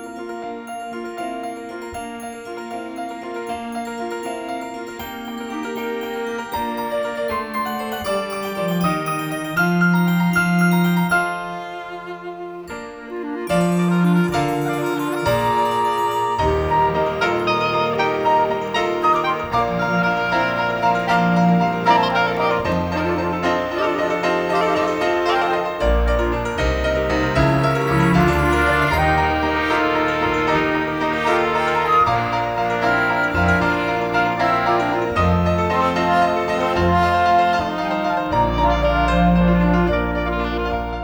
INSTRUMENTAL AUDITION TRACKS - USE FOR RECORDING